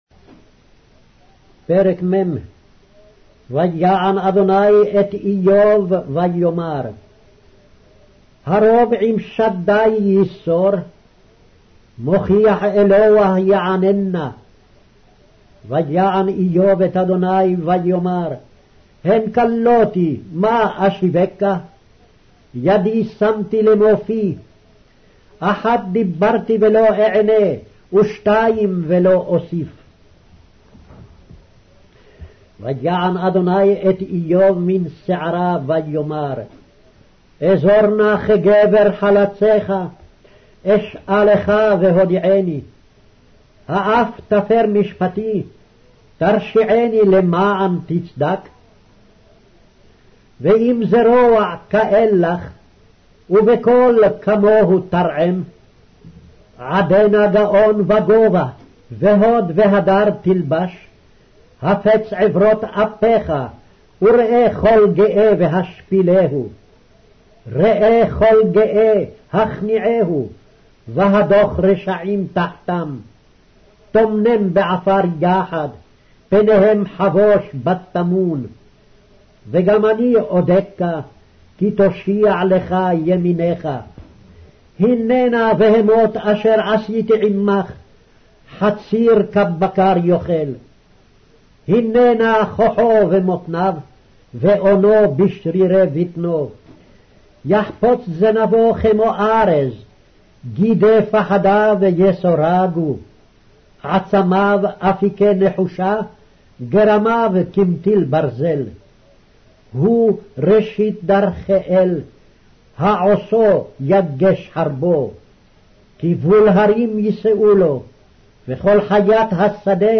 Hebrew Audio Bible - Job 23 in Ocvkn bible version